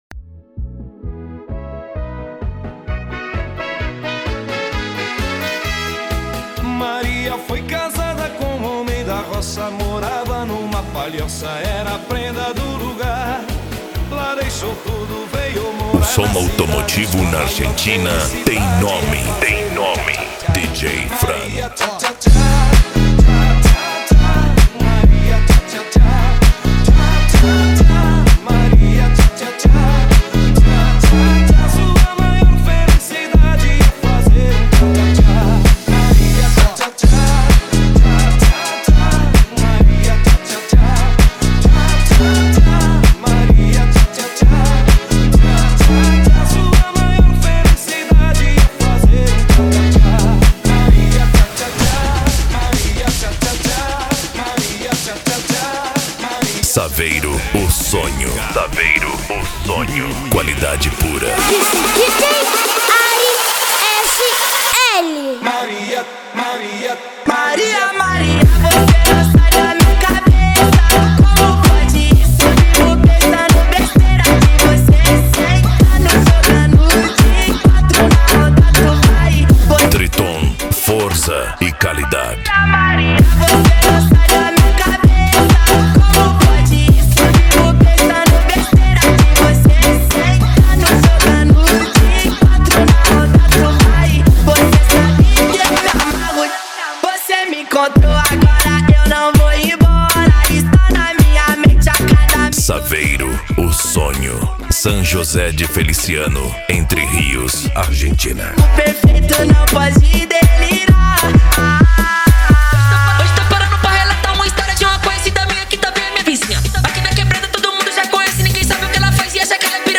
Bass
Funk
SERTANEJO